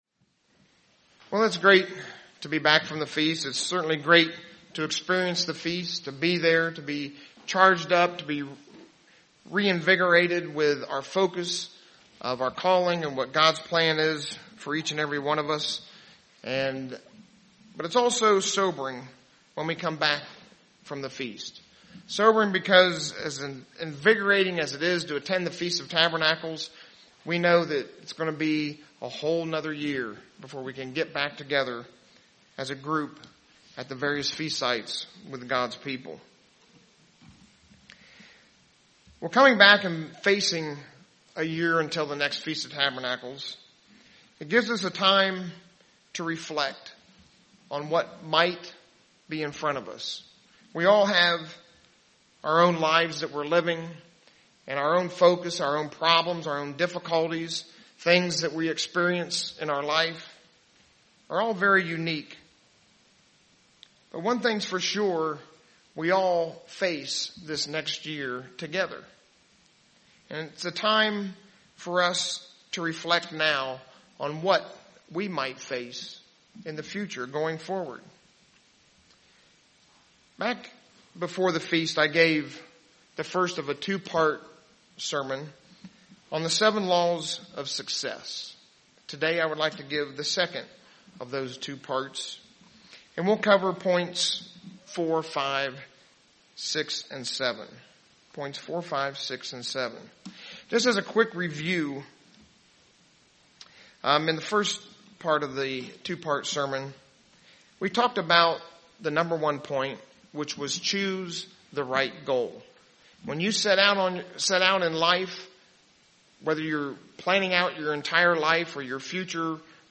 Continuation of the sermon on the seven laws of success.